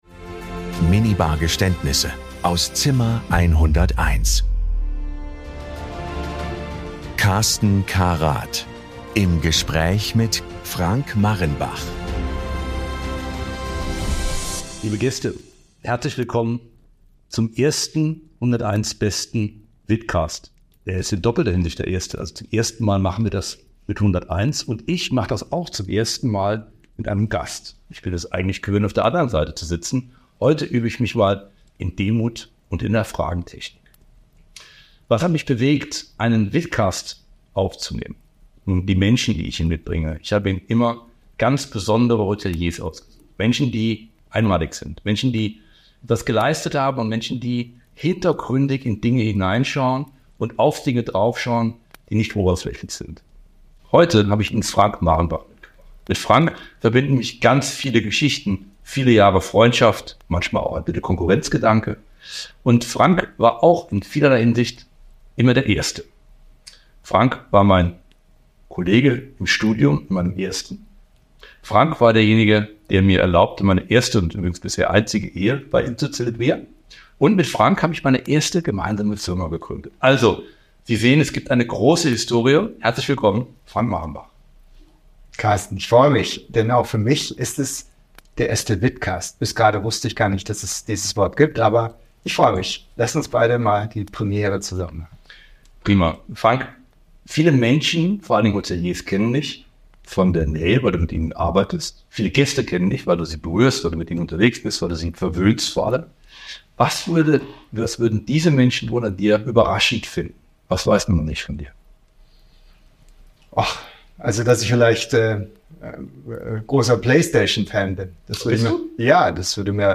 Ein Gespräch über Verantwortung und was es braucht, um eine Hotelgruppe langfristig erfolgreich zu führen und weiterzuentwickeln.